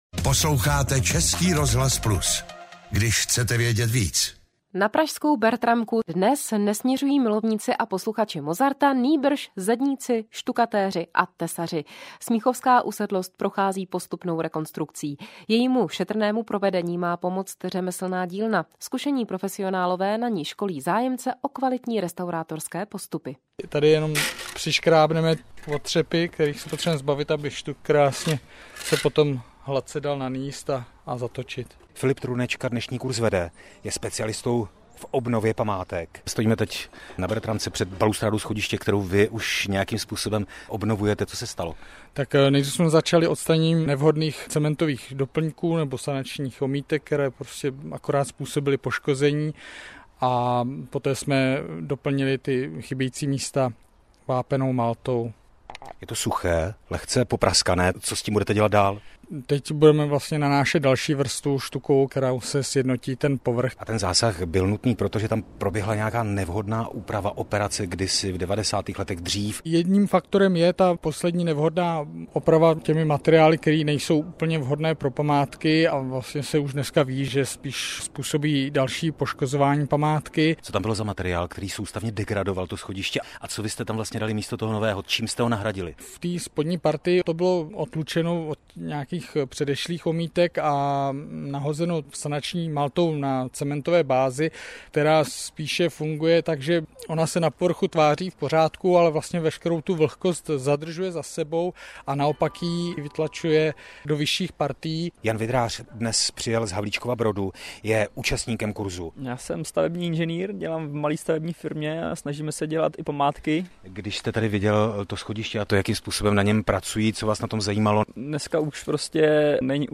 reportaz-radio_CRo_Plus-mq.mp3